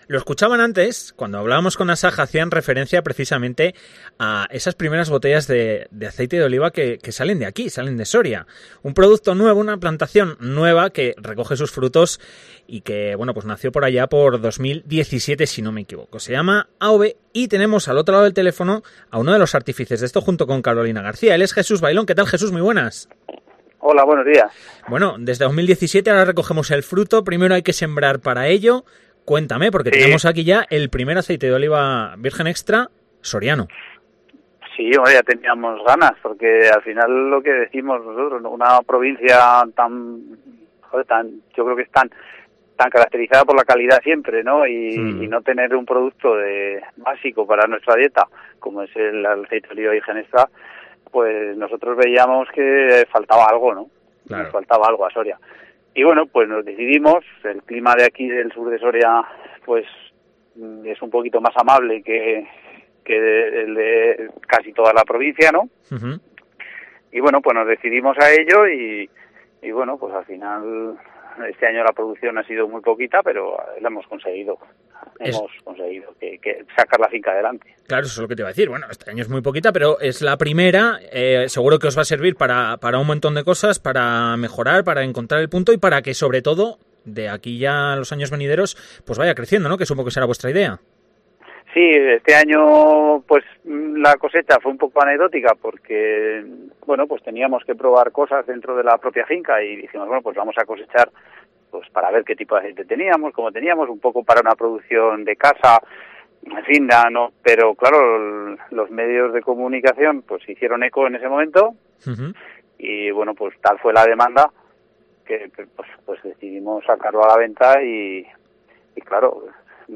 Entrevista. Olivo de Soria, el primer aceite virgen extra soriano